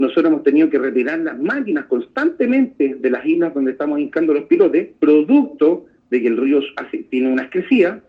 Eso sí, los constantes retrasos a raíz de las persistentes lluvias, generaban la inquietud de los vecinos. James Fry, Seremi de Obras Públicas, se refería a los imprevistos.